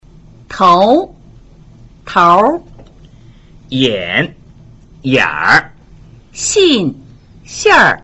頭 tu (腦袋)    頭兒 tur (領頭人)
眼 yn (眼睛)   眼兒 ynr (窟窿)
信 xn (信)      信兒 xnr (消息)